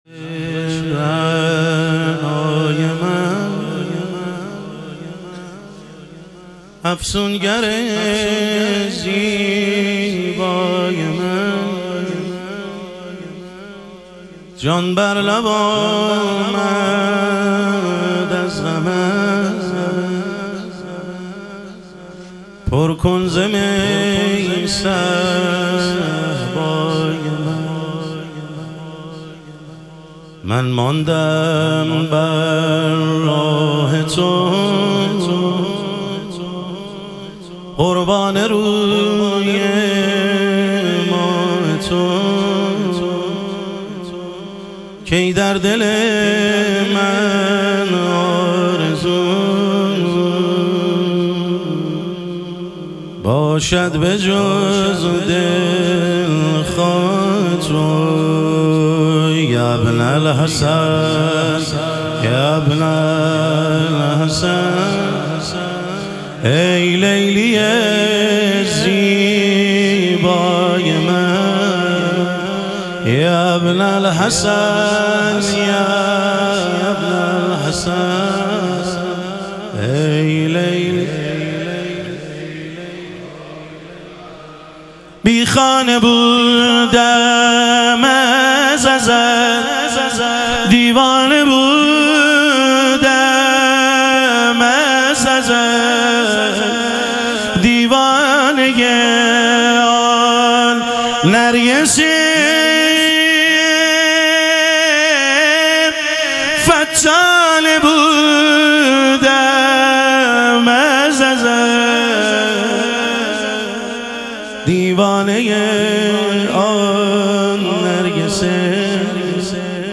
ظهور وجود مقدس حضرت مهدی علیه السلام - مدح و رجز